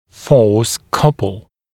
[fɔːs ‘kʌpl][фо:с ‘капл]пара сил, парные силы